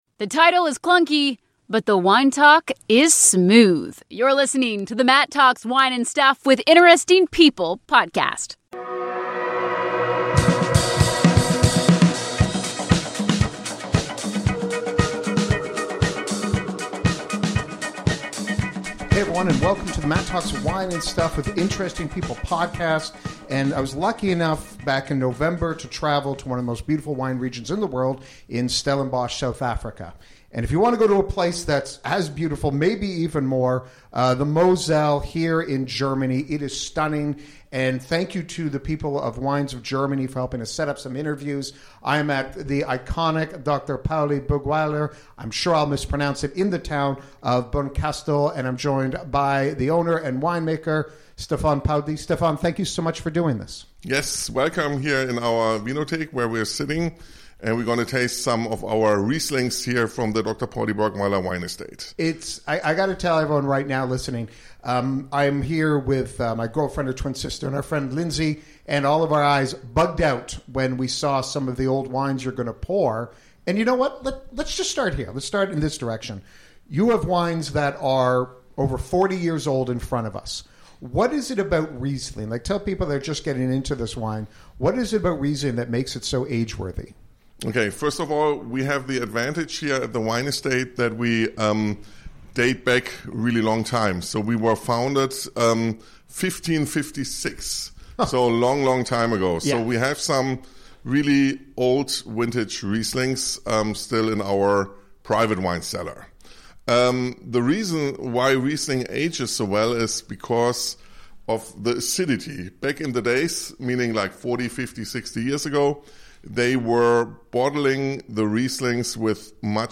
my first interview in the Mosel